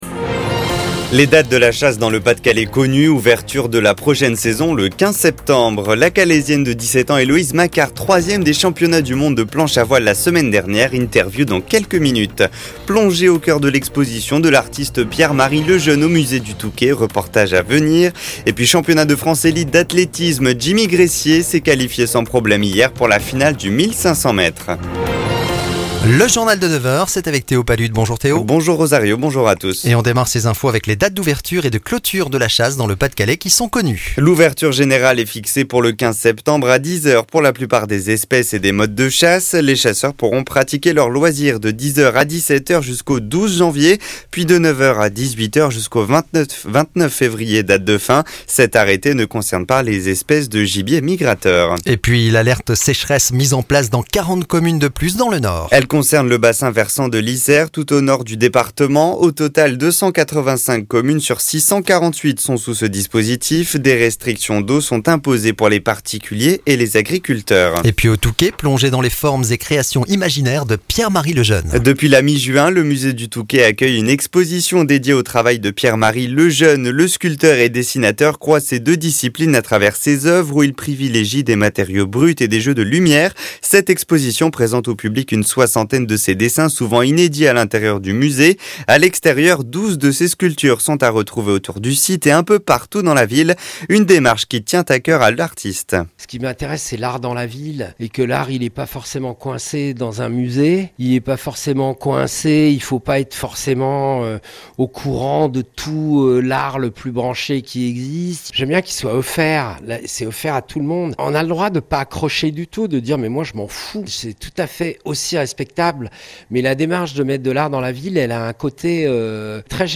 Le journal Cote d'Opale du samedi 27 juillet
Retrouvez l'essentiel de l'actualité de la Côte d'Opale et les grands titres des infos en France et dans le monde en 6 minutes. Journal de 9h.